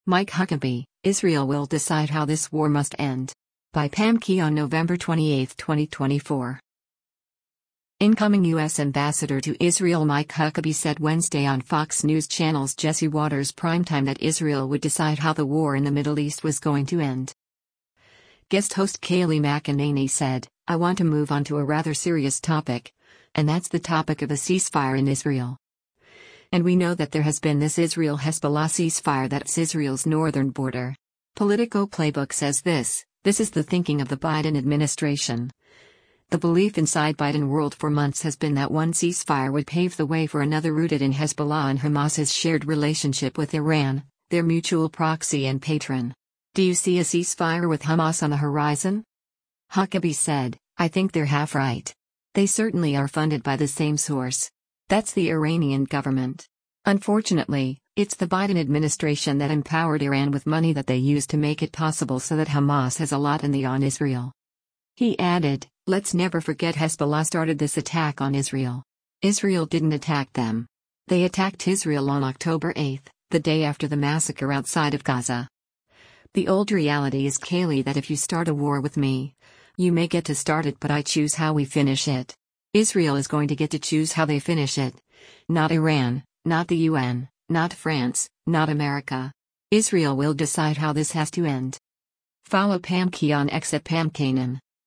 Incoming U.S. Ambassador to Israel Mike Huckabee said Wednesday on Fox News Channel’s “Jesse Watters Primetime” that Israel would decide how the war in the Middle East was going to end.